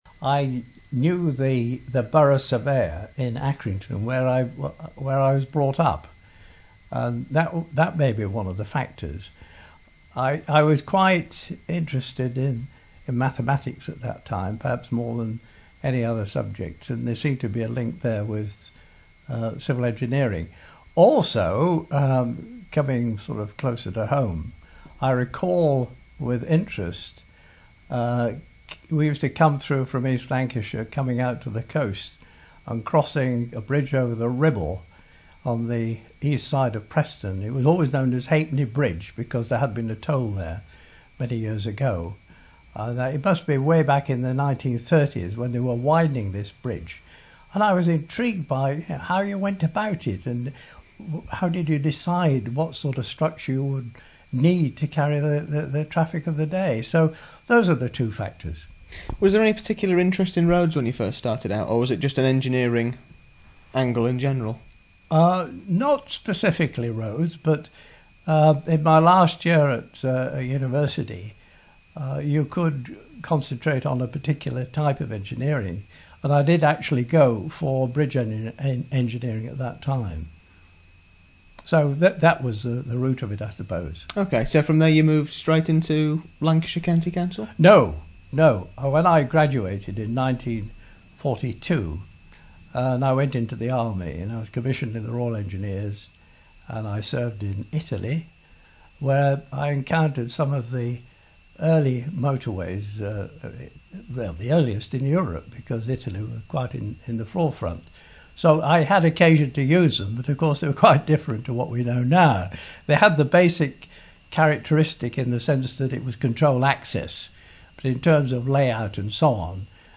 hy_interview.mp3